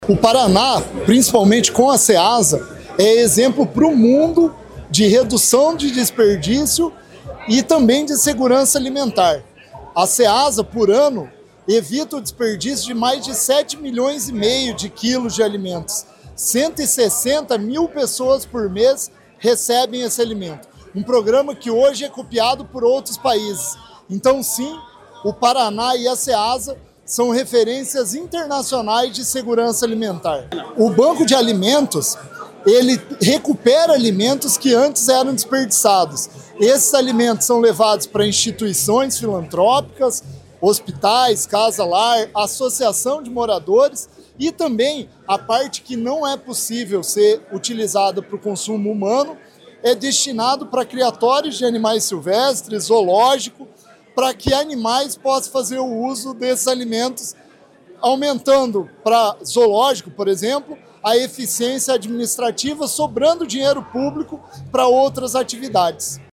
Sonora do diretor-presidente da Ceasa Paraná, Éder Bublitz, sobre os programas do Estado que garantem segurança alimentar